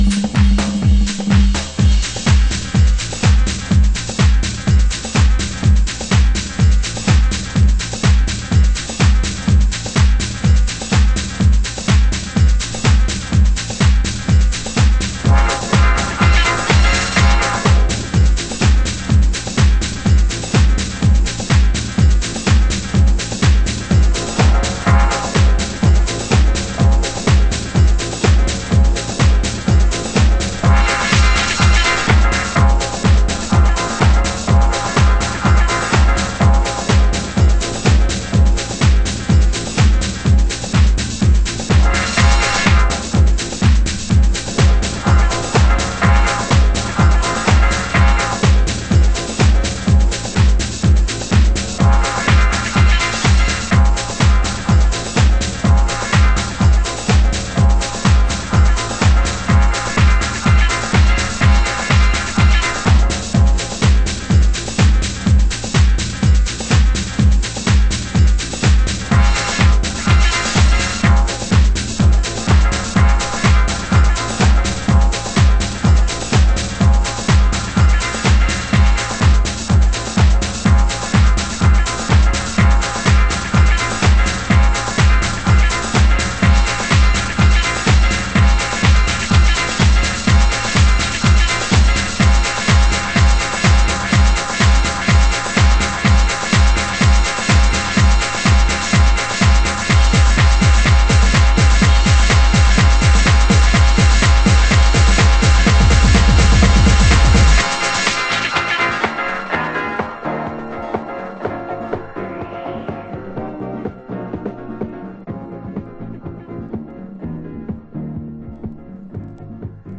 盤質：軽いスレ傷有/少しチリパチノイズ有